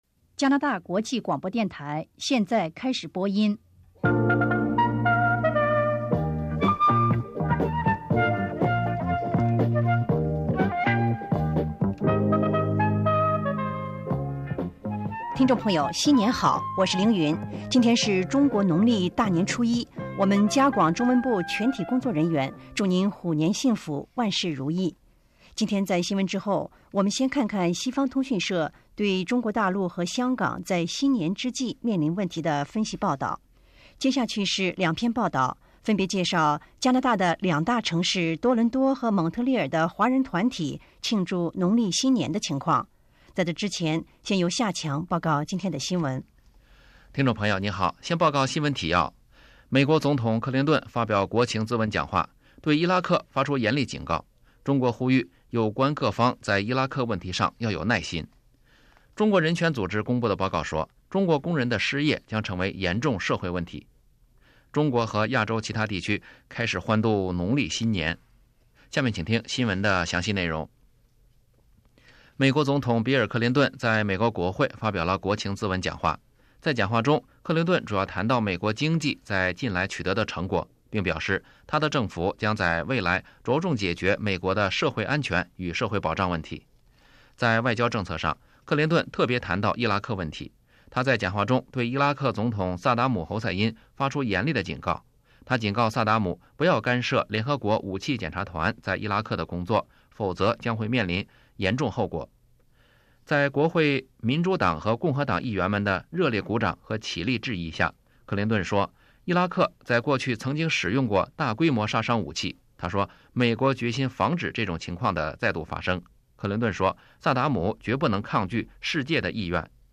1998年2月8日RCI中文新闻时事节目